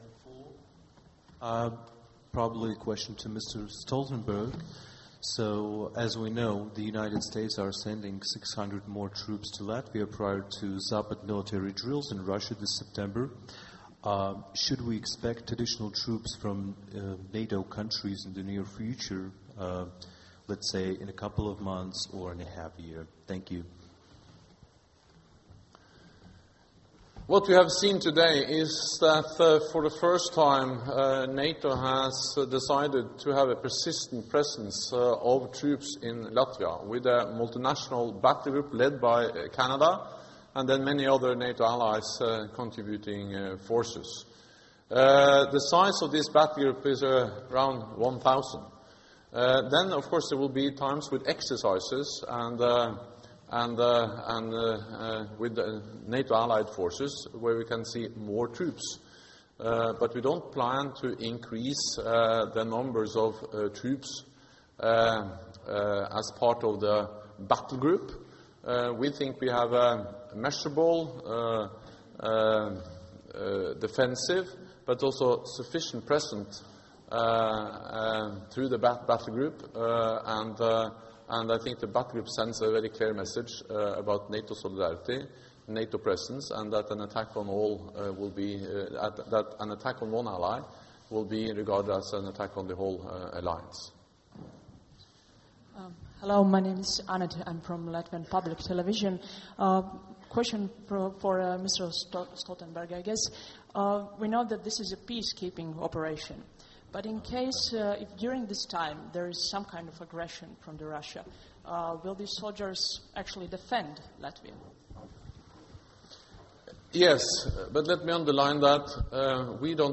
Joint press conference with NATO Secretary General Jens Stoltenberg; the President of Latvia, Raimonds Vējonis; the Minister of Defence of Canada, Harjit Sajjan and the Minister of Defence, Mr. Raimonds Bergmanis (Camp Ādaži, Latvia)
Q&A session following the joint press conference with NATO Secretary General Jens Stoltenberg, the President of Latvia, Raimonds Vējonis; the Minister of Defence of Canada, Harjit Sajjan and the Minister of Defence, Mr. Raimonds Bergmanis 19 Jun. 2017 | download mp3